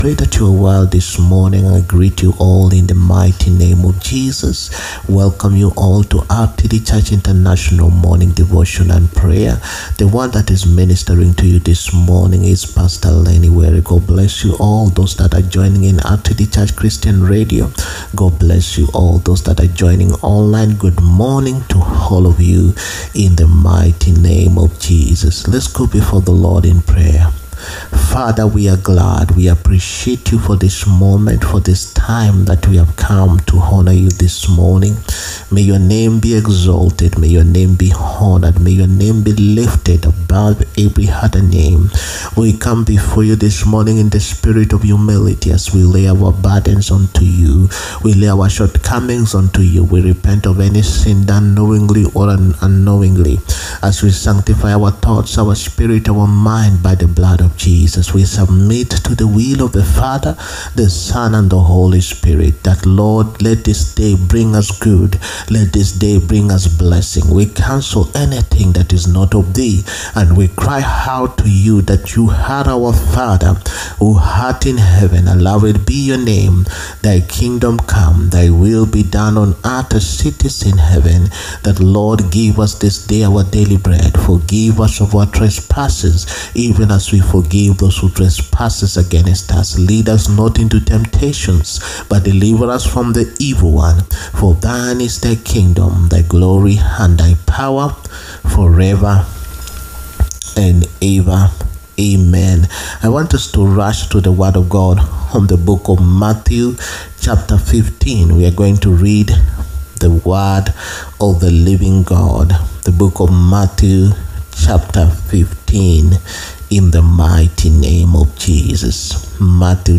MORNING DEVOTION AND PRAYERS. MATHEW CHAPTER 15. PART 1.
MORNING-DEVOTION-AND-PRAYERS.-MATHEW-CHAPTER-15.-PART-1.mp3